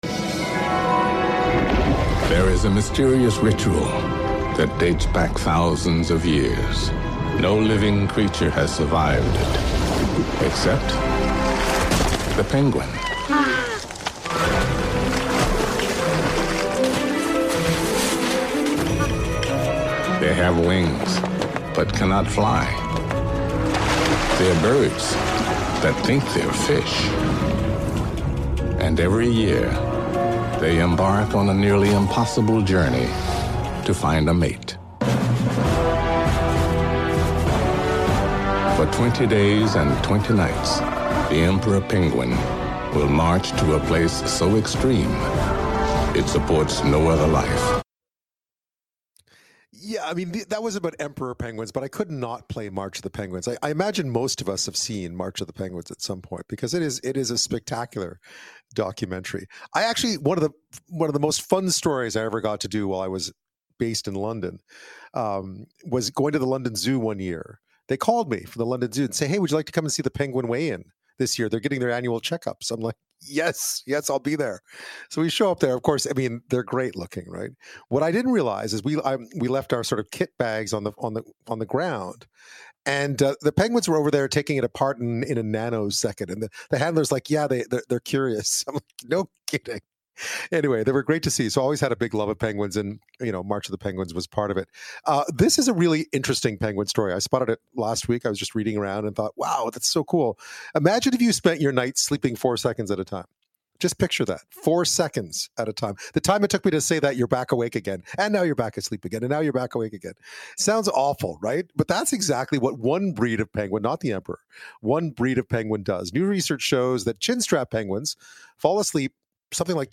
Radio_Interview_Canada.mp3